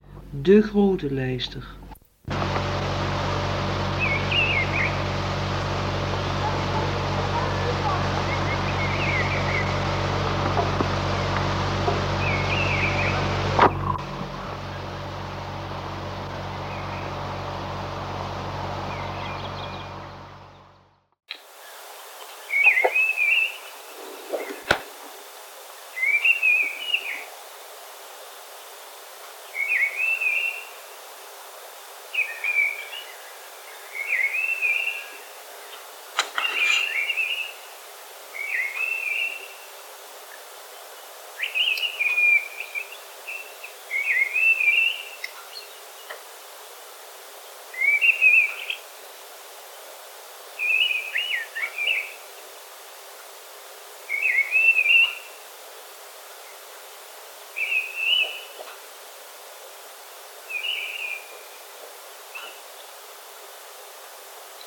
De prachtige toon, waardoor het bijna melancholisch wordt, ook van hem de rust, maar ook de kracht.
De Grote Lijster
De Grote Lijster (en een jeugdige ik…)
ps: Vroeger ging ik er op uit om vogelgeluiden op te nemen.
Het is me gelukt het bandje te digitaliseren en zo prijkt mijn stem, samen met de Grote Lijster op deze site…
Grote-Lijster.mp3